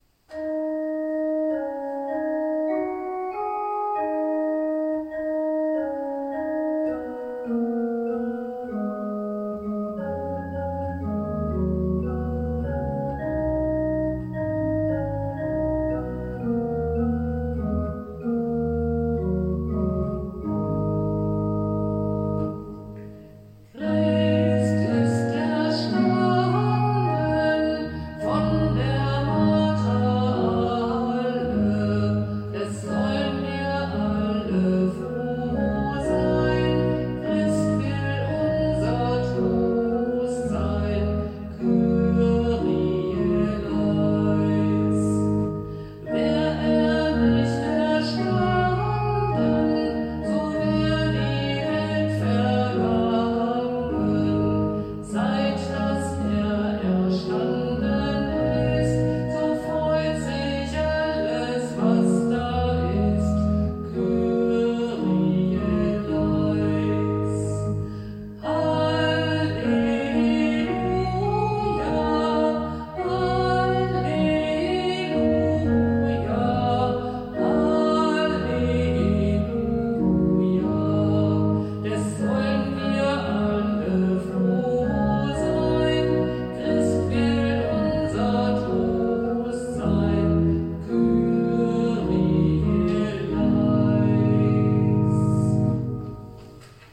• Christ ist erstanden (Orgel)